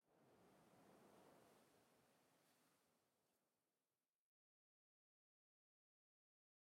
assets / minecraft / sounds / block / sand / wind9.ogg
wind9.ogg